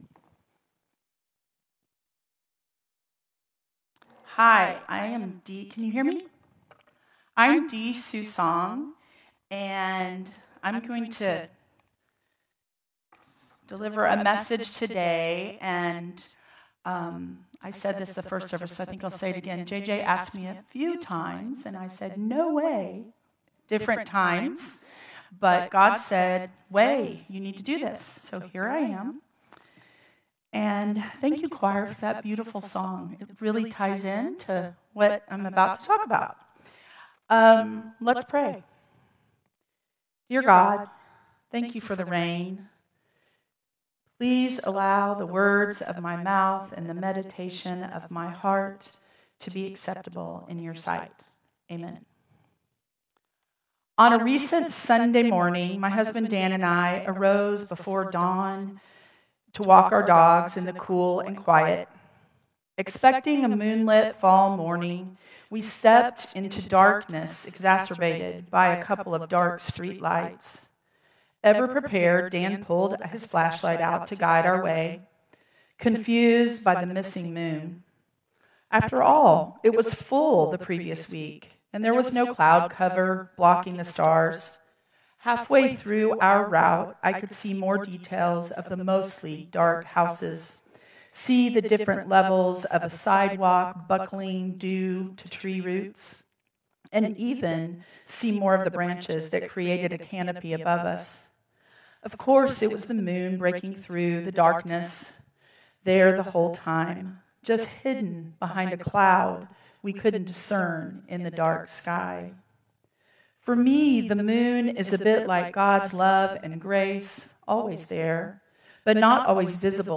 Traditional Service 10/26/2025